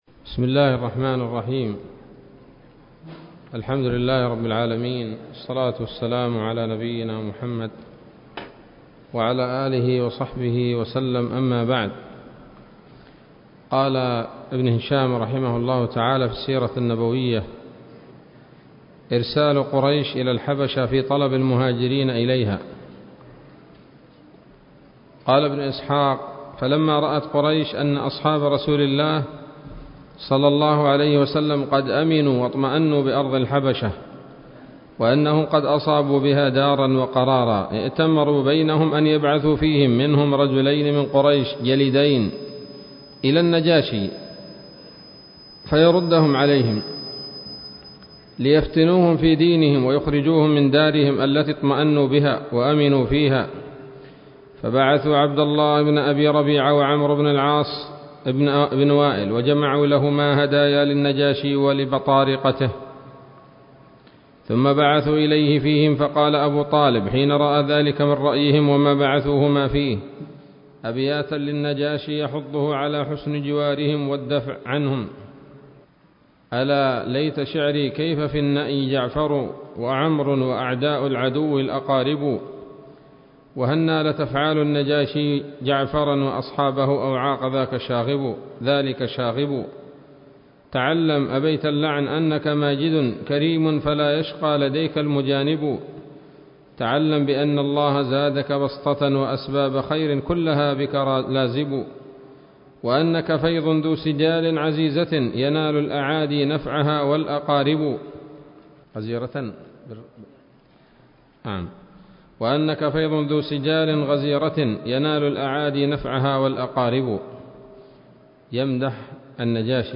الدرس الخامس والثلاثون من التعليق على كتاب السيرة النبوية لابن هشام